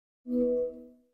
Звук завершения звонка в FaceTime